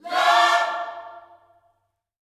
LOVECHORD6.wav